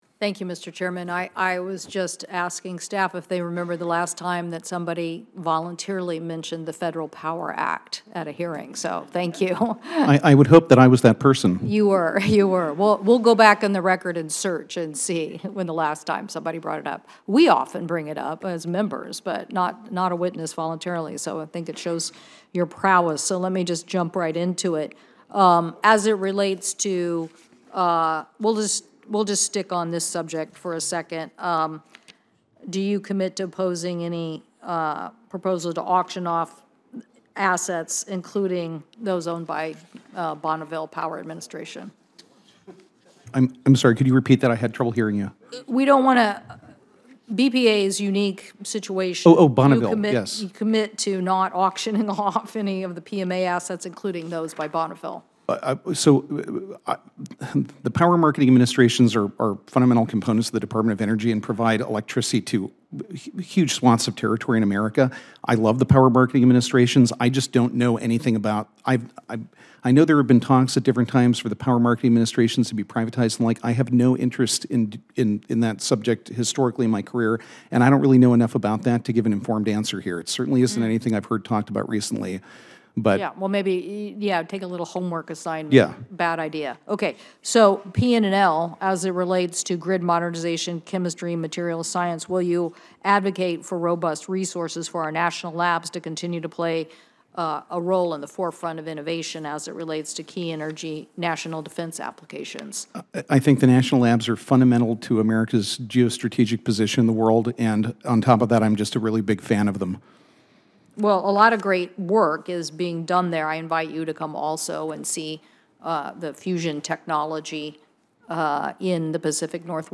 WASHINGTON, D.C. – Yesterday, U.S. Senator Maria Cantwell (D-WA), senior member of the Senate Finance Committee and ranking member of the Senate Committee on Commerce, Science, and Transportation, pressed James Danly and Katharine MacGregor – President Trump’s nominees to serve as Deputy Secretary of the Department of Energy (DOE) and Deputy Secretary of the Department of the Interior (DOI) – on their commitments to not sell off public assets owned by Bonneville Power Administration after DOGE recently ordered the sale of the BPA Portland building.